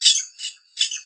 由JCG Musics于2015年创建的名为AmbiGen的装置中使用的单个鸟啁啾和短语。
Tag: 鸟鸣声 自然 现场记录